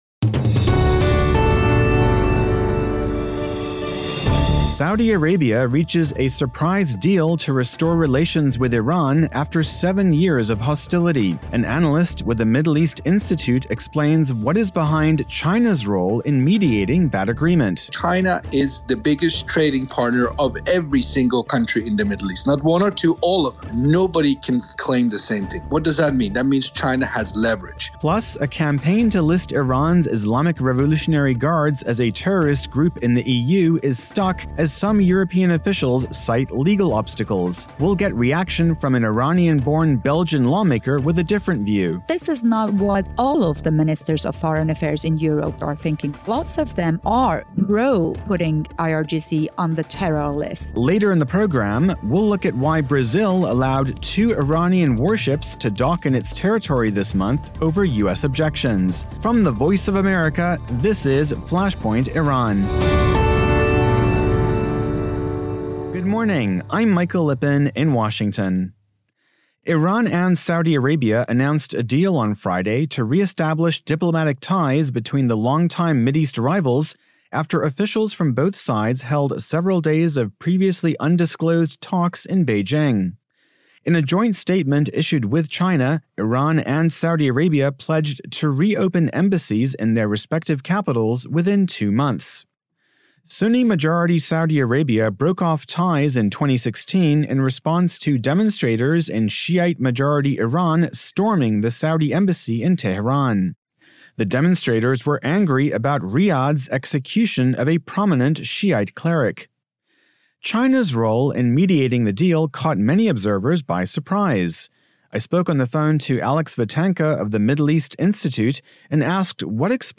In interview for VOA’s Flashpoint Iran podcast, Darya Safai says EU foreign ministers are seeking to resolve obstacles to the designation of Iran’s Islamic Revolutionary Guard Corps as a terrorist organization